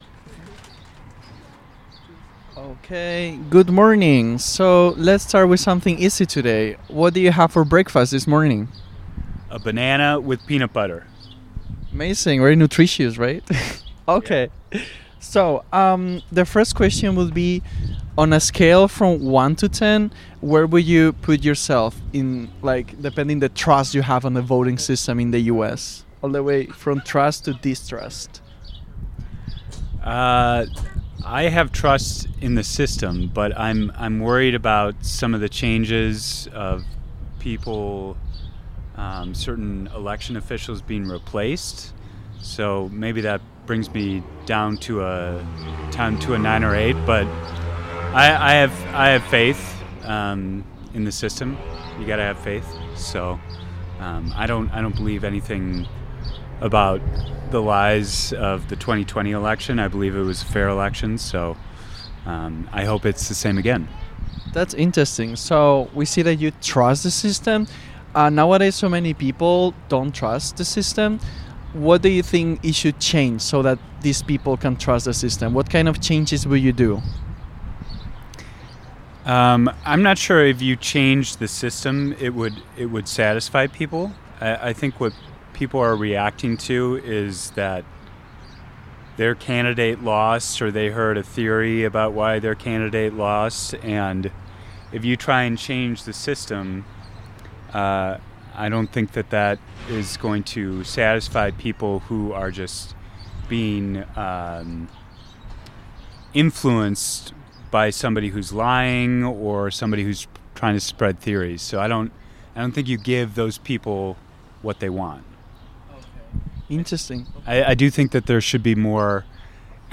Location Outpost Natural Foods